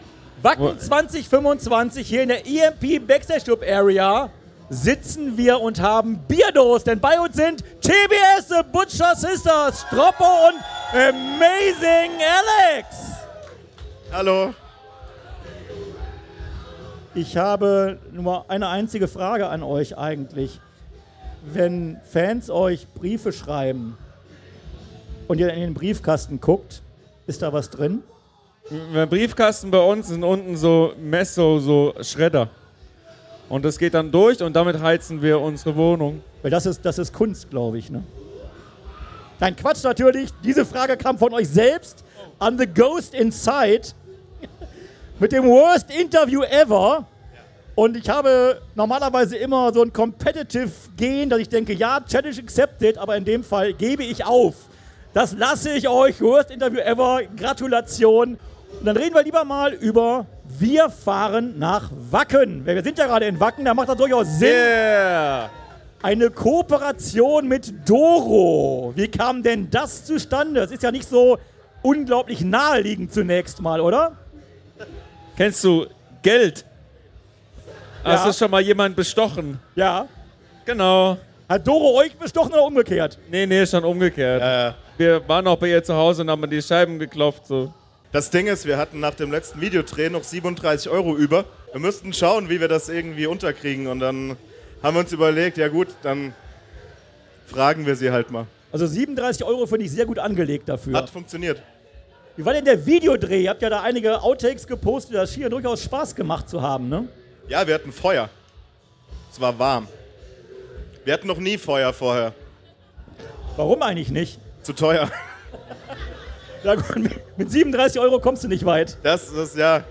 Wacken 2025 Special - THE BUTCHER SISTERS - Live aus der EMP Backstage Club Area